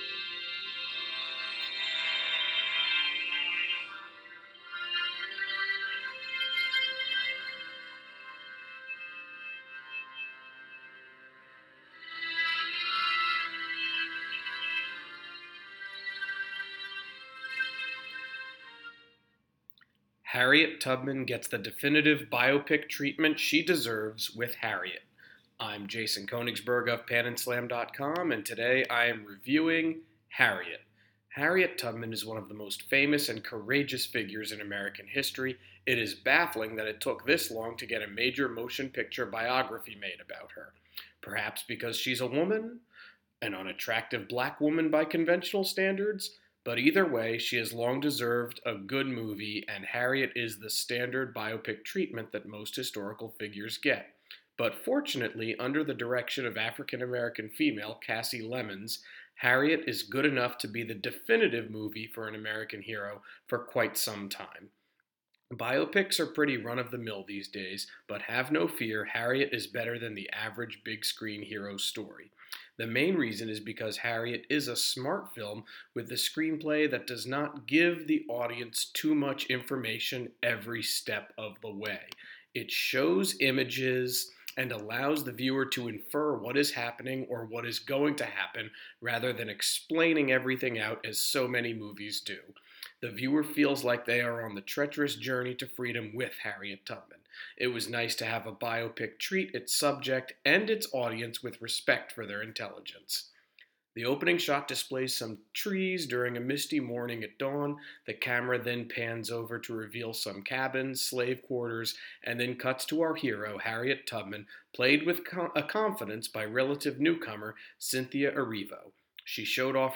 Movie Review: Harriet